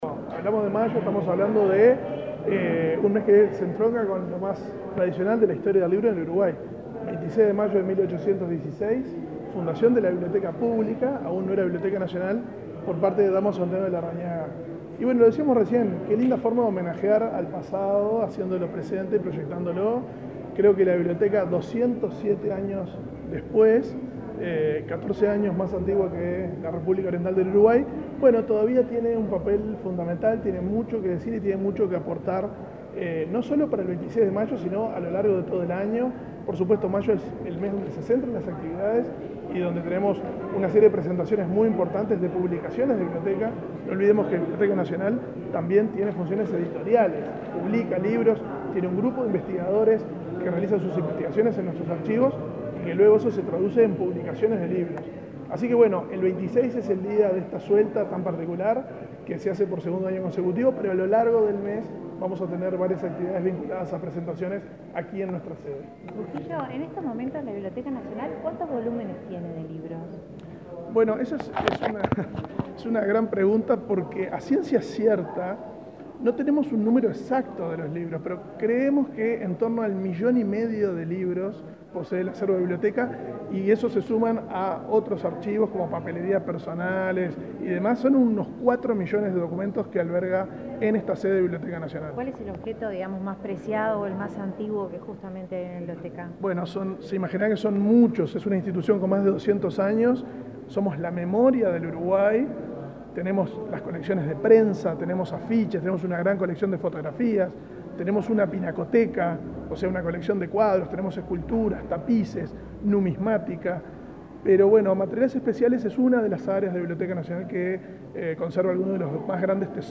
Declaraciones del director de la Biblioteca Nacional
Declaraciones del director de la Biblioteca Nacional 02/05/2023 Compartir Facebook Twitter Copiar enlace WhatsApp LinkedIn El ministro de Educación y Cultura, Pablo da Silveira, y el director de la Biblioteca Nacional, Valentín Trujillo, presentaron los detalles de la edición 2023 del Día Nacional del Libro. Luego Trujillo dialogó con la prensa.